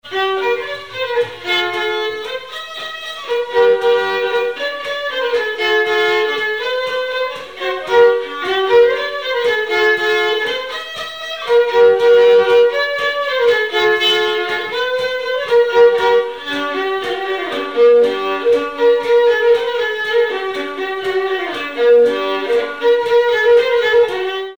Marche
danse : marche
circonstance : bal, dancerie
Pièce musicale inédite